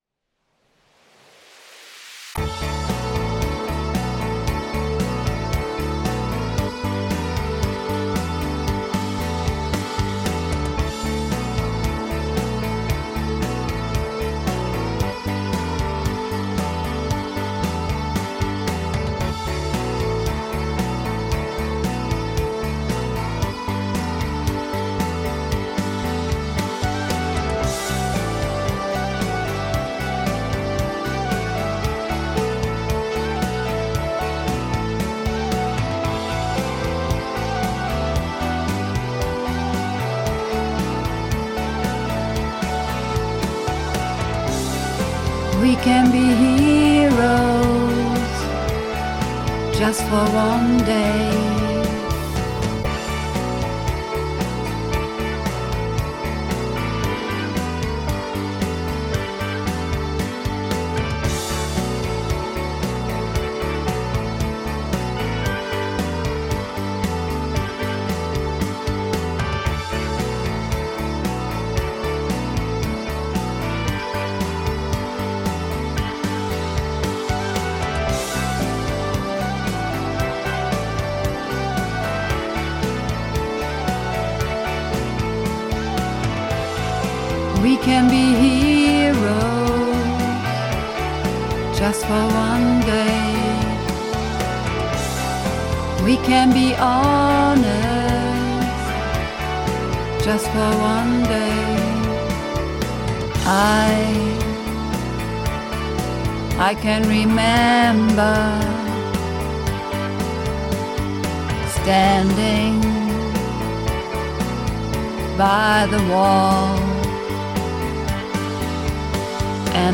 Übungsaufnahmen - Heroes
Heroes (Alt - "And the Shame..." - Tief)
Heroes__1_Alt_Shame_Tief.mp3